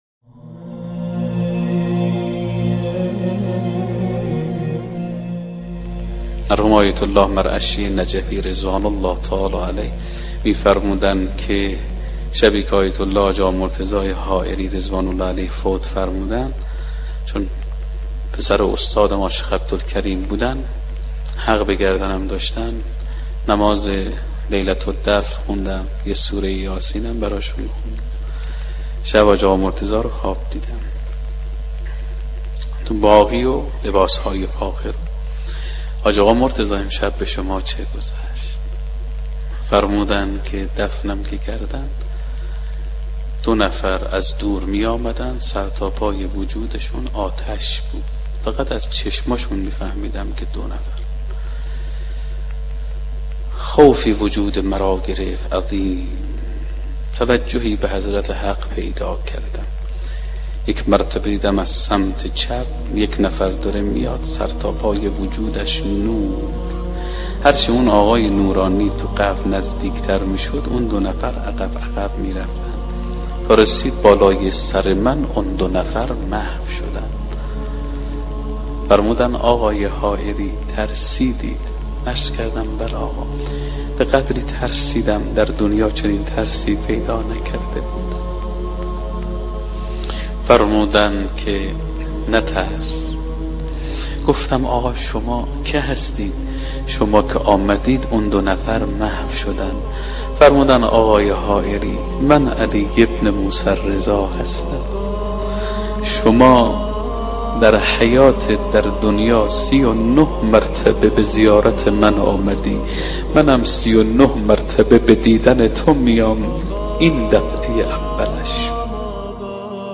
قطعه ای از سخنرانی حجت الاسلام هاشمی‌نژاد به مناسبت شهادت امام رضا (ع) منتشر می شود.